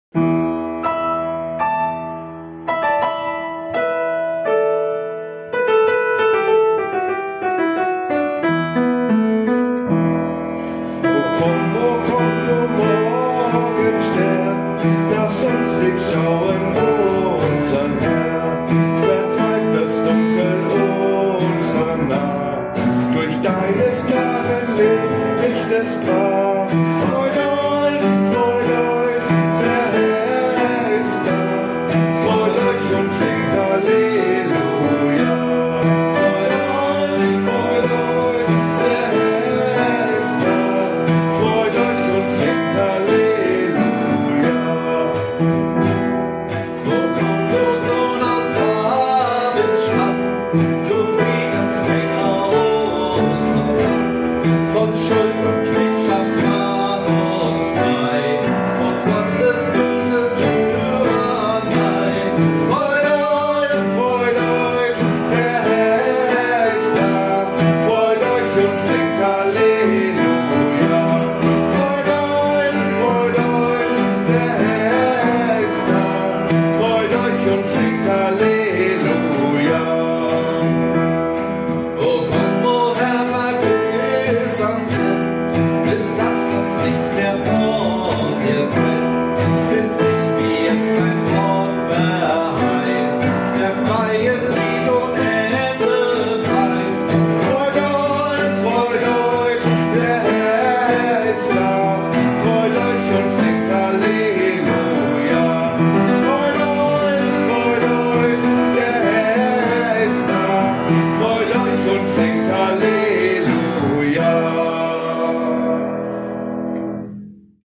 Gesang, Gitarre
Klavier